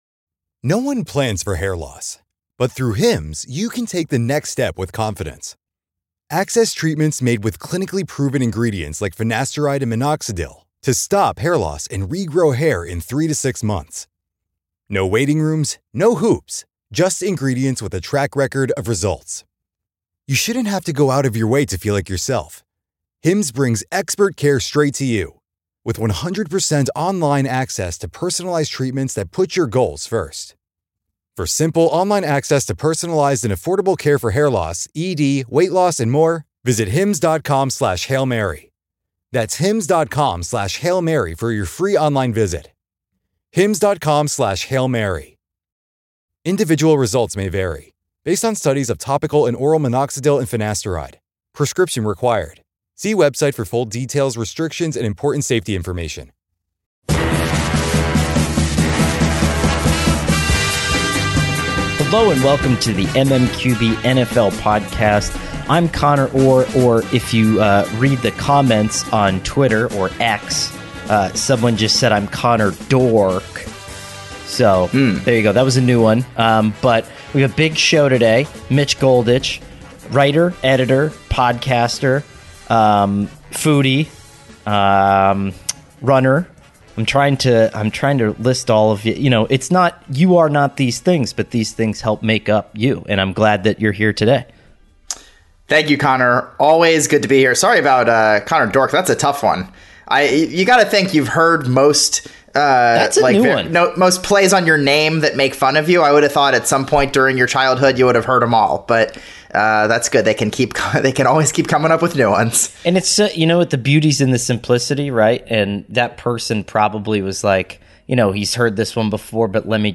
Sports, Football